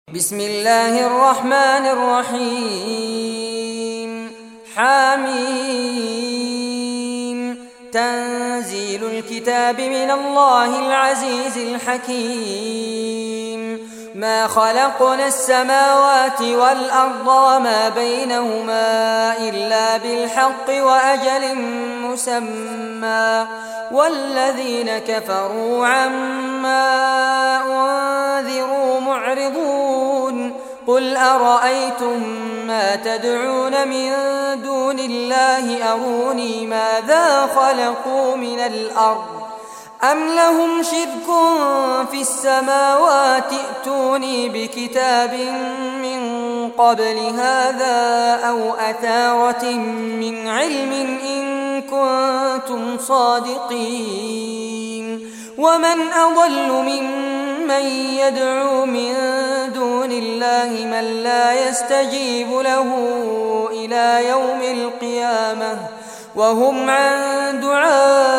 Surah Al-Ahqaf Recitation by Fares Abbad
Surah Al-Ahqaf, listen or play online mp3 tilawat / recitation in Arabic in the beautiful voice of Sheikh Fares Abbad.